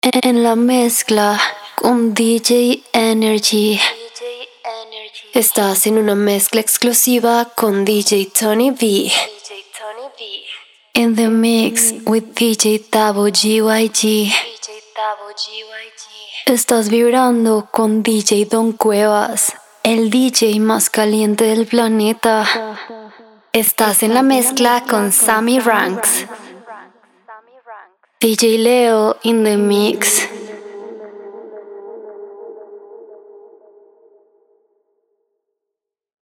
Spanish Female DJ Drops
DJ Drops por voz femenina española
Destaca con nuestros drops personalizados de DJ femeninos en español, grabados profesionalmente por una artista de voz colombiana. Acento auténtico.